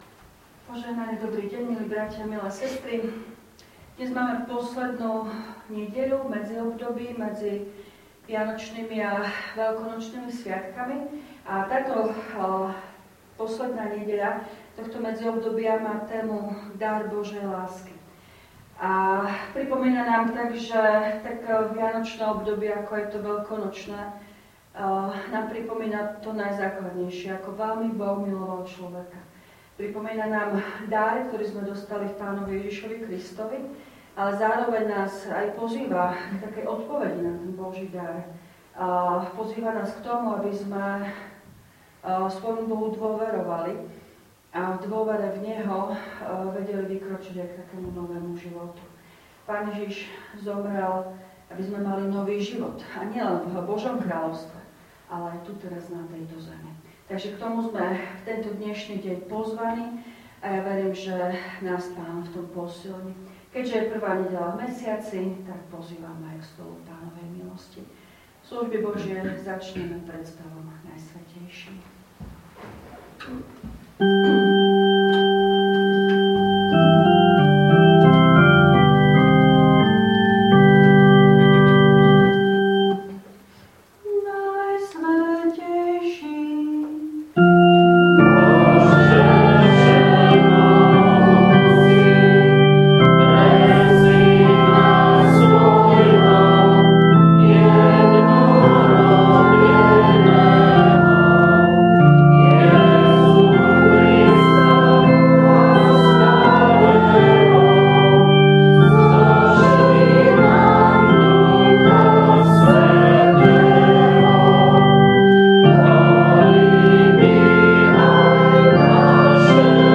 Služby Božie – Predpôstna nedeľa
V nasledovnom článku si môžete vypočuť zvukový záznam zo služieb Božích – Predpôstna nedeľa.
Piesne: 385, 525, 303, 356, A86.